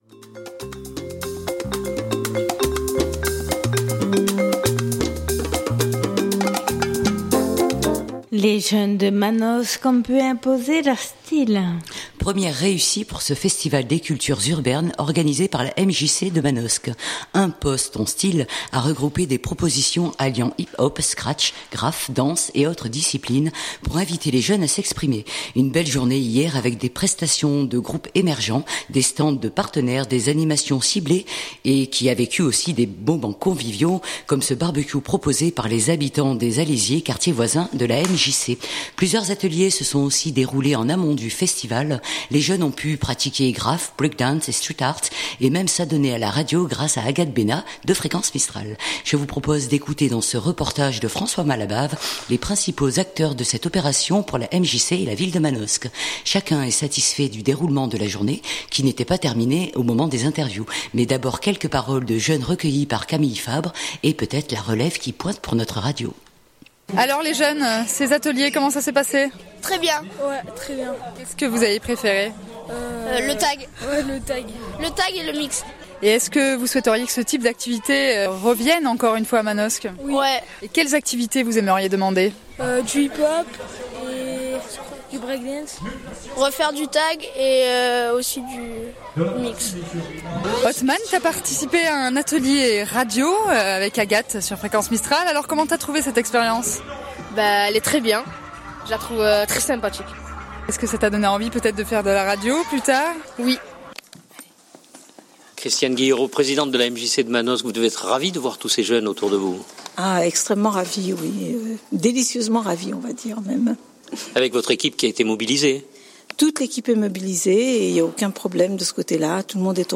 Chacun est satisfait du déroulement de la journée qui n’était pas terminée au moment des interviews.